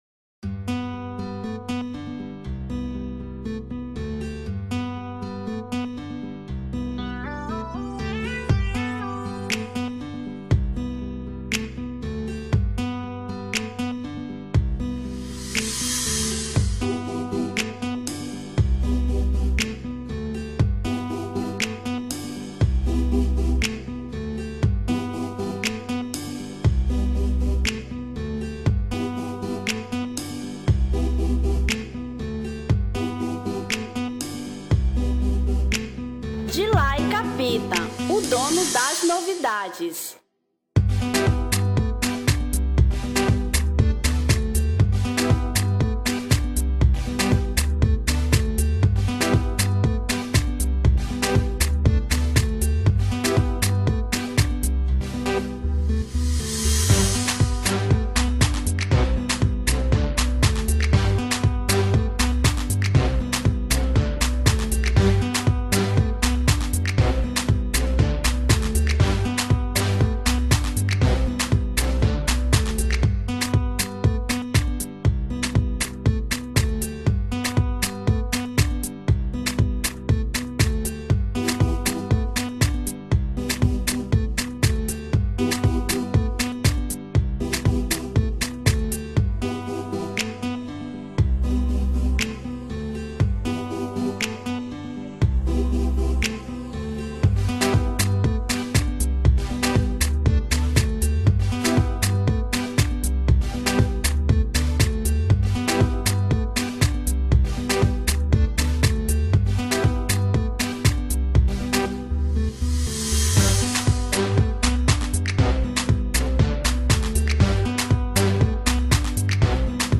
Instrumental 2018